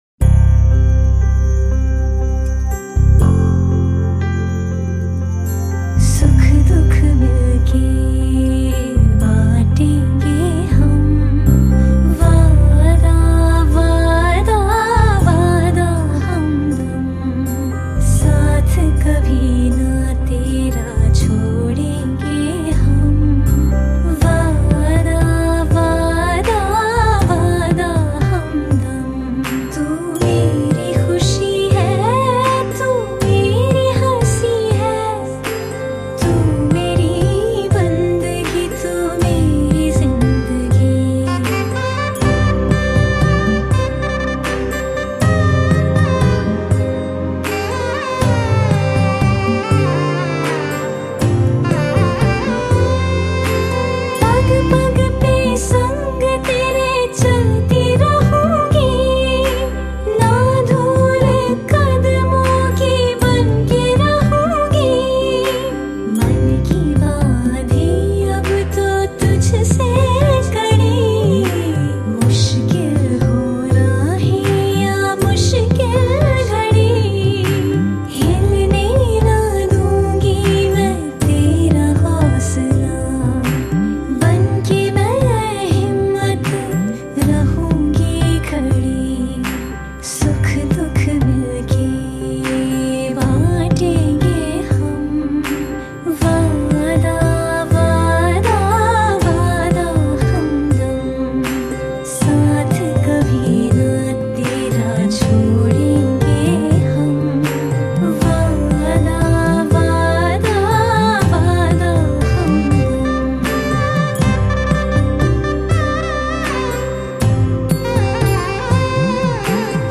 Low Quality